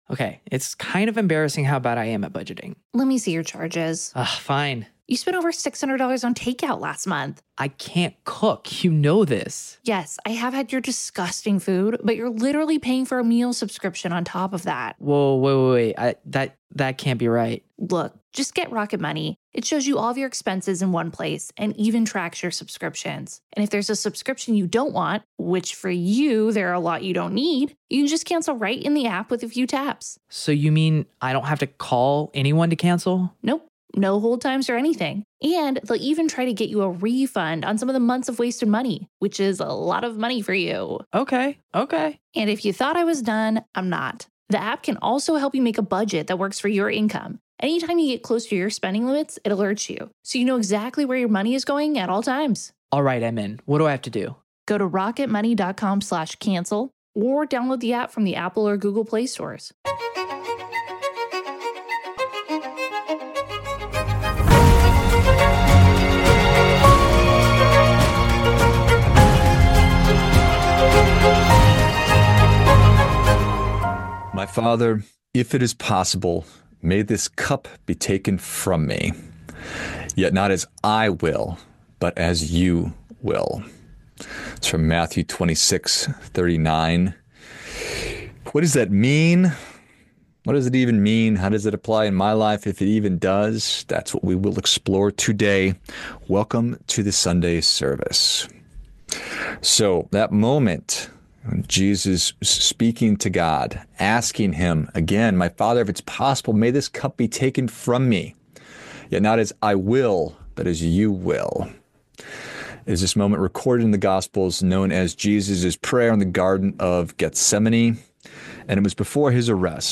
Sunday Service: Finding Strength in Uncertainty